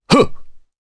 Crow-Vox_Jump_jp_b.wav